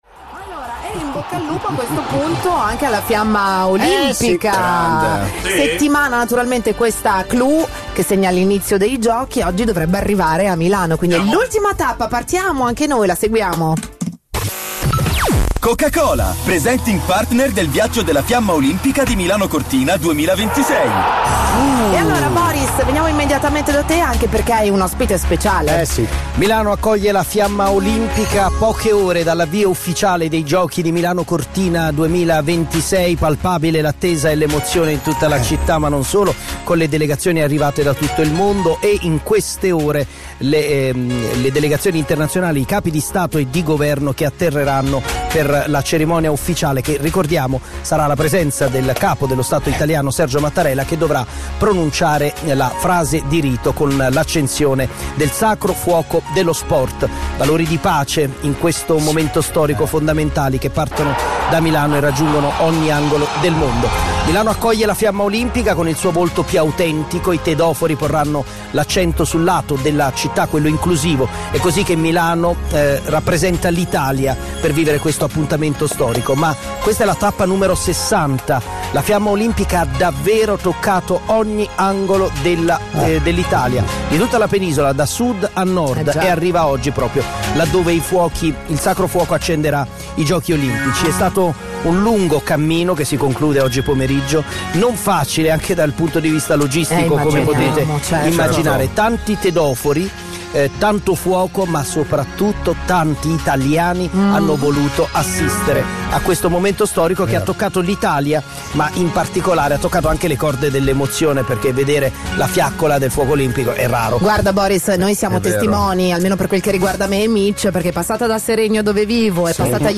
questa mattina è stato ospite di Radio 105